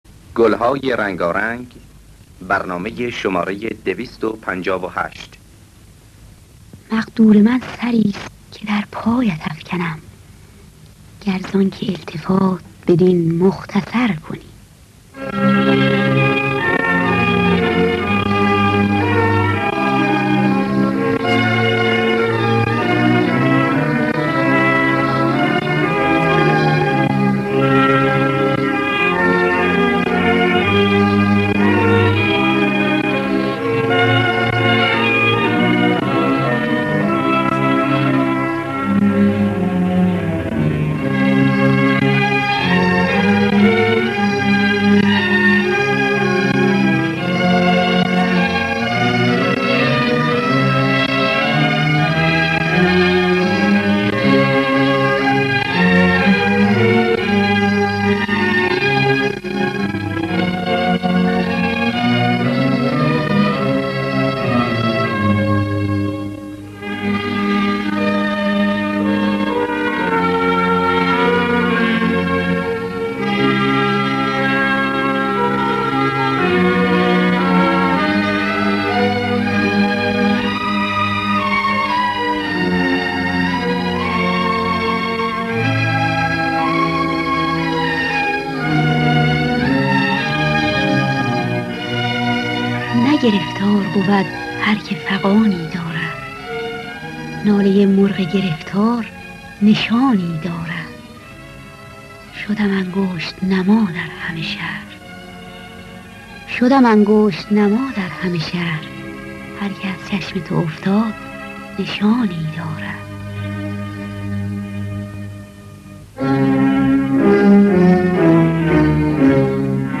گلهای رنگارنگ ۲۵۸ - افشاری